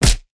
face_hit_small_01.wav